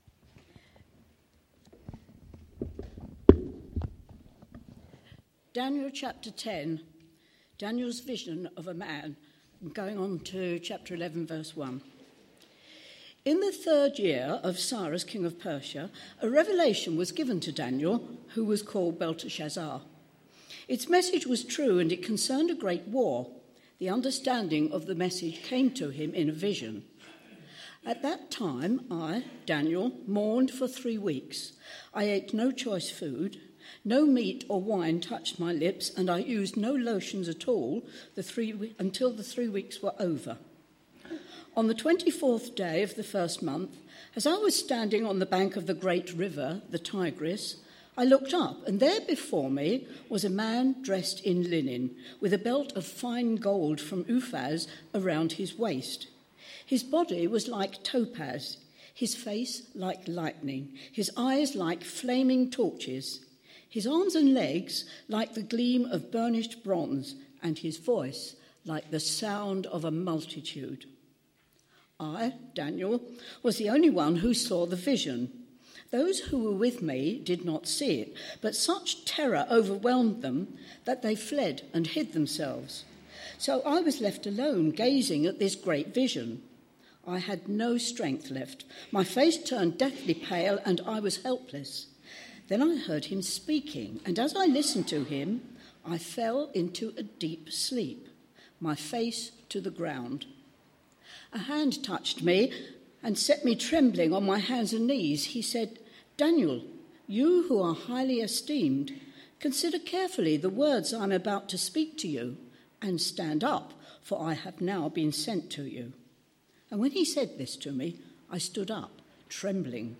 A message from the series "The Book of Daniel."